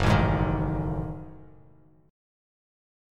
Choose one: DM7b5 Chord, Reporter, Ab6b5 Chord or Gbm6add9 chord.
Gbm6add9 chord